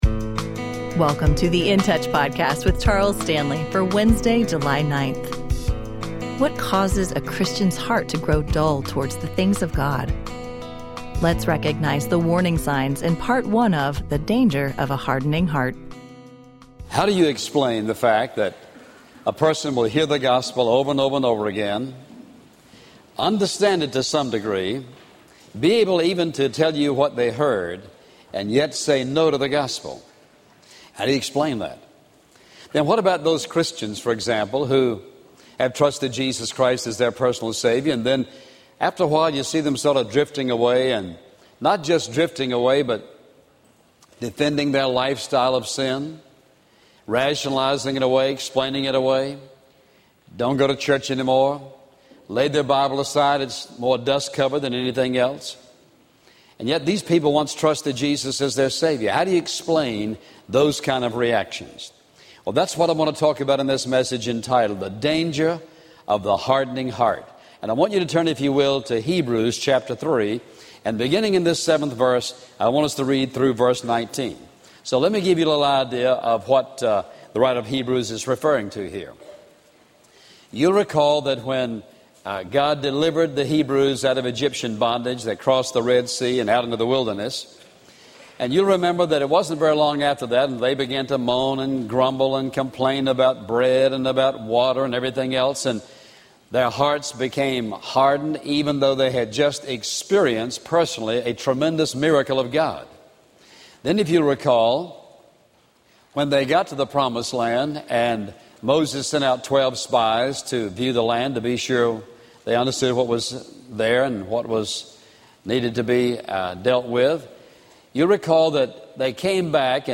Dr. Stanley highlights the dangers of a hardened heart as he teaches from Exodus 20 and Mark 6.